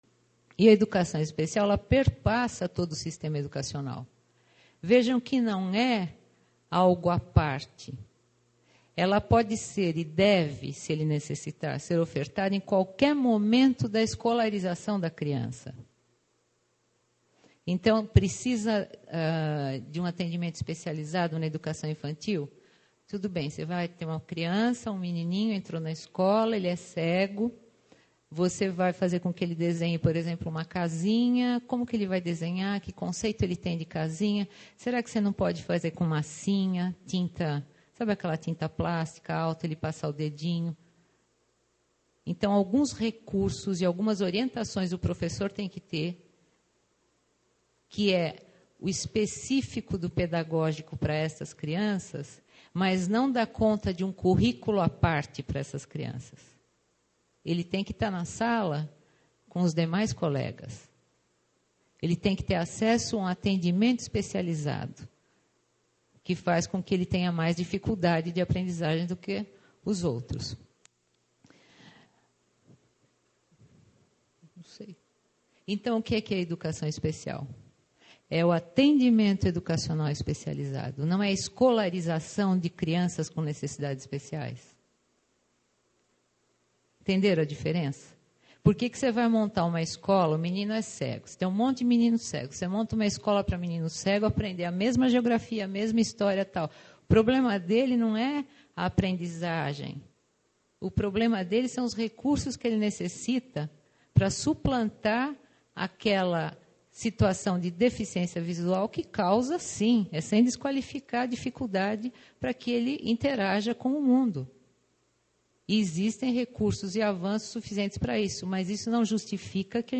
Durante o evento, que contou mais de 400 participantes, falou-se sobre a legislação em vigor, as experiências aplicadas, pesquisas realizadas, sobre os compromissos e responsabilidades da escola e do professor, sobre formação docente.